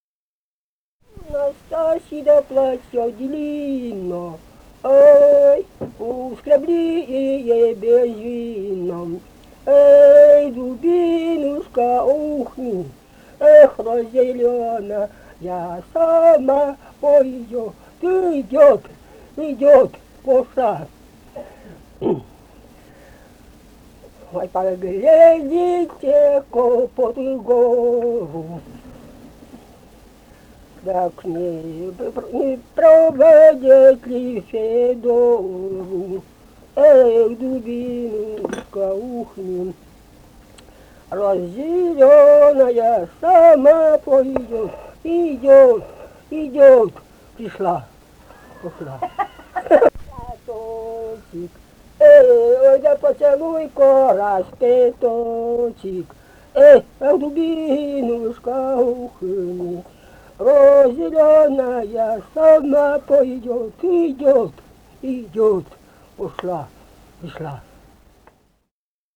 «У Настасьи платье длинно» (трудовая «Дубинушка»).
Вологодская область, д. Савинская Тигинского с/с Вожегодского района, 1969 г. И1130-53